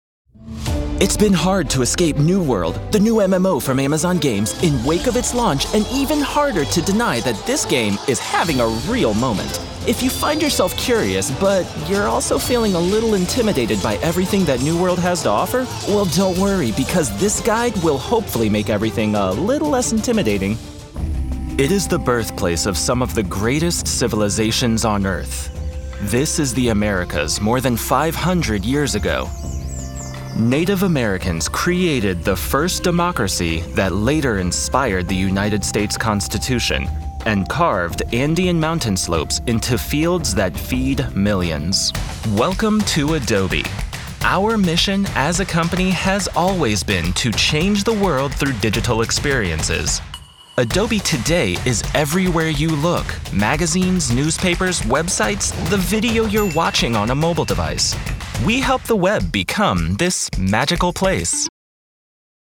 Inglés (Estados Unidos)
Narración
Adulto joven
Mediana edad